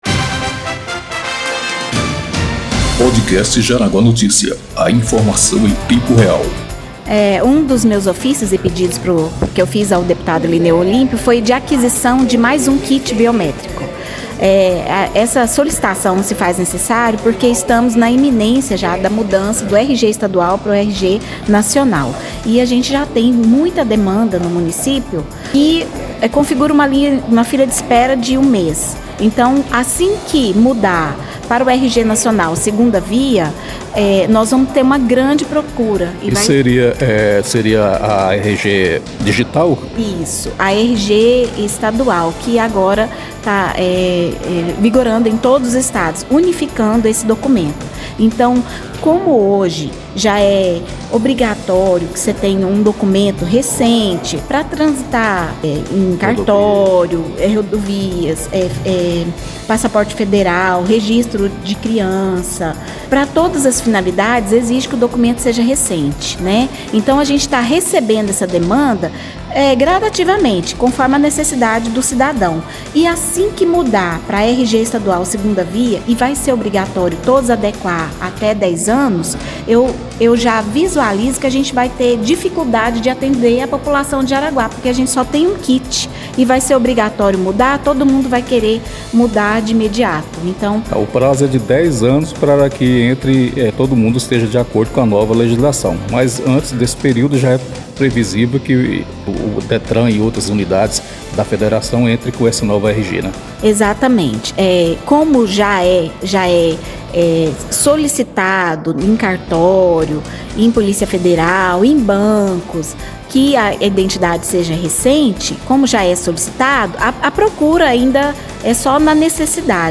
ENTREVISTA-IDELMA.mp3